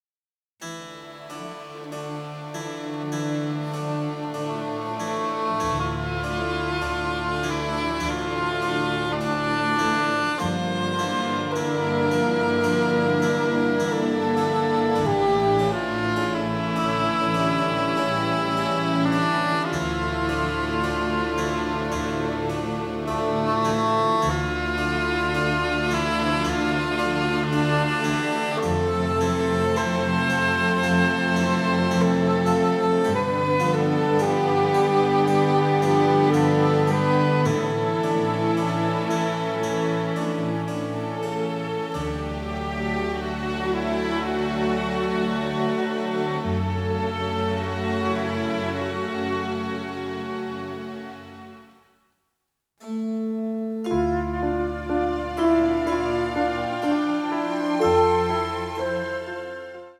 beautiful, nostalgic
radiantly passionate score